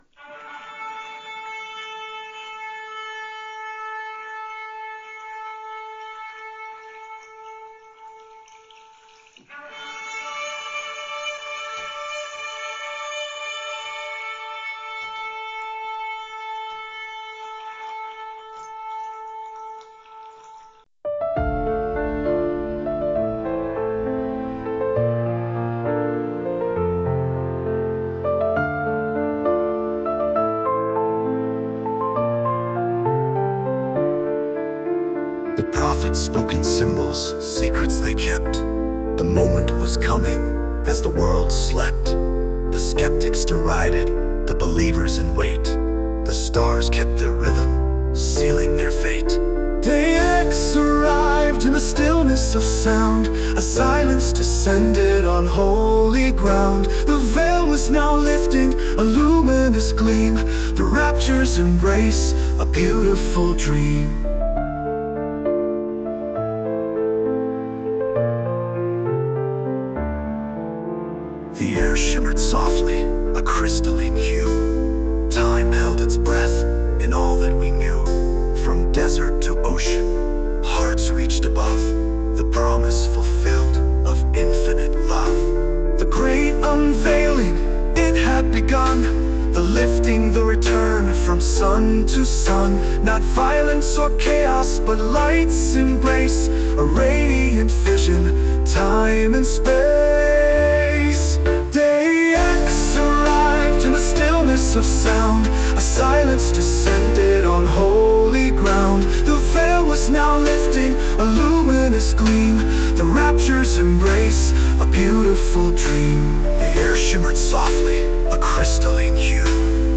(A soaring, hopeful melody, incorporating a sense of both wonder and peace)